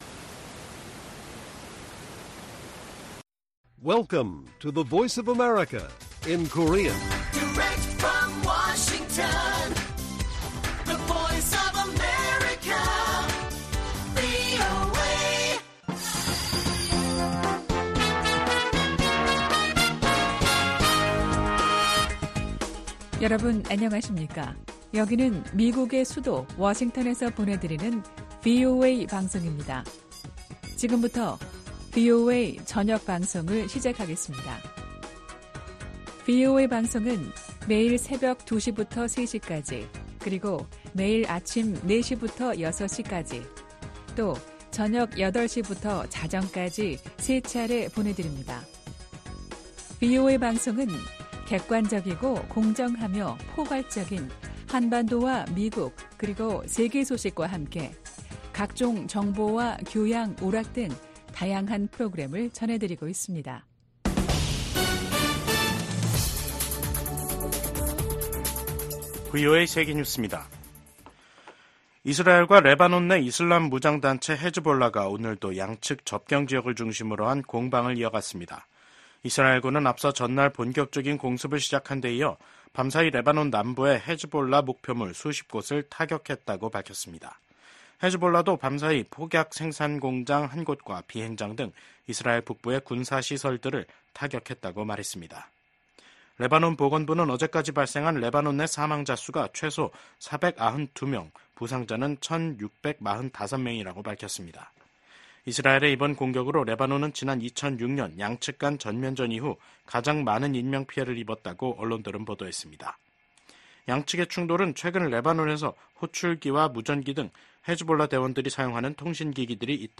VOA 한국어 간판 뉴스 프로그램 '뉴스 투데이', 2024년 9월 24일 1부 방송입니다. 미한일 3국이 외교장관 회의를 개최하고 ‘정치적 전환기’ 속 변함 없는 공조 의지를 확인했습니다. 미국 정부는 북한의 7차 핵실험이 정치적 결정만 남은 것으로 평가한다고 밝혔습니다.